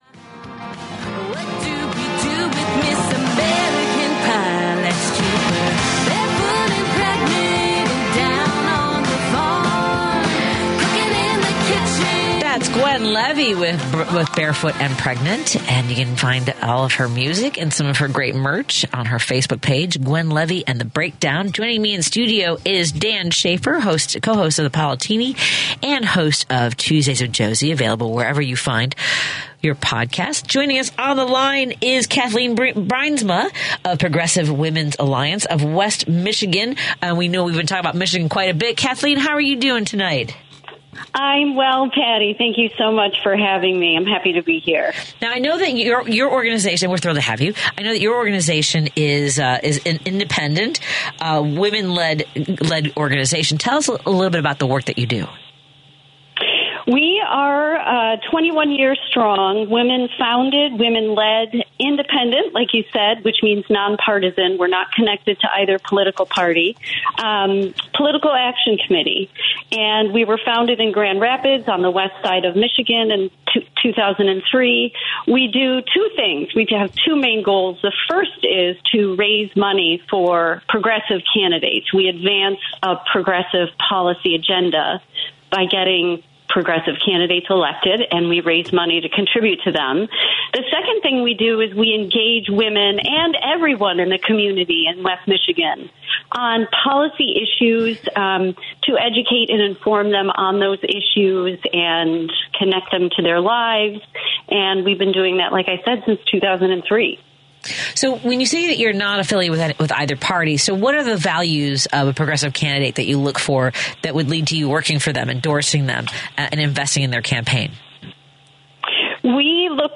Podcast interview "Driving It Home